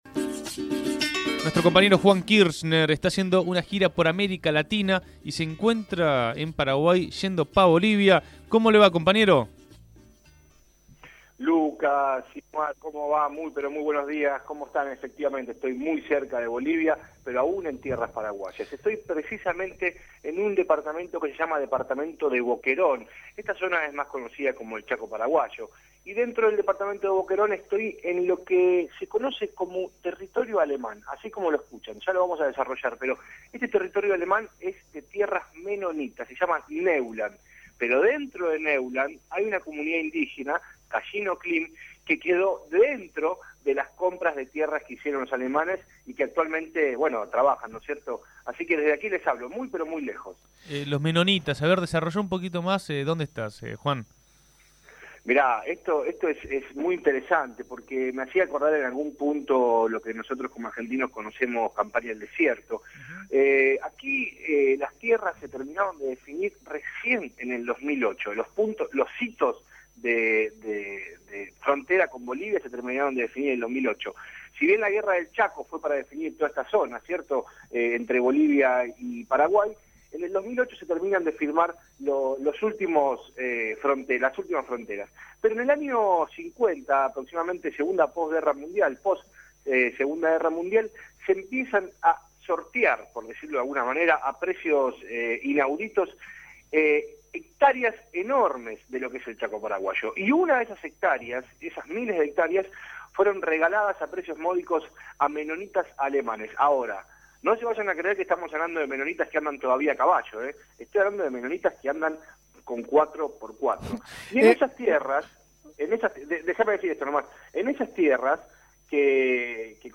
En su gira por América Latina, desde el Chaco Paraguayo, contó la historia de esas tierras y la situación de los habitantes que las trabajan.